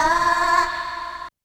Tm8_Chant53.wav